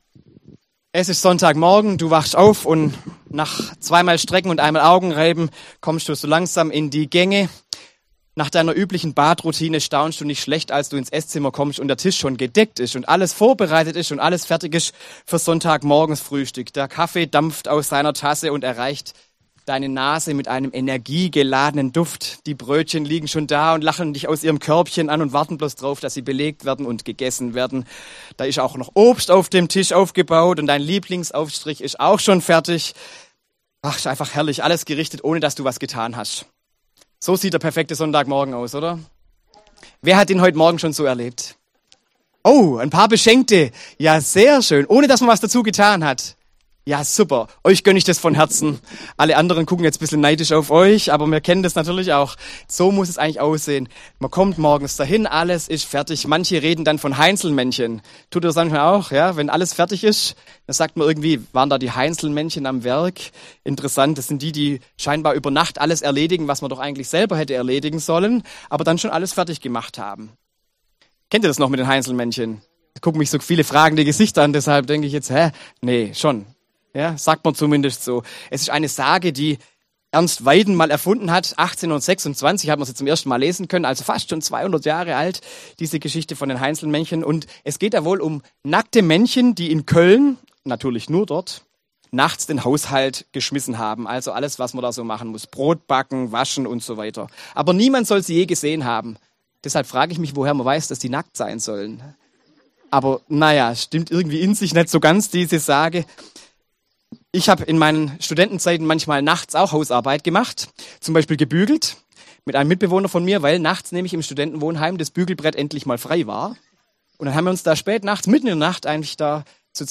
Predigt zur Erntebitte am 3. Sonntag nach Trinitatis